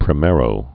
(prĭ-mârō)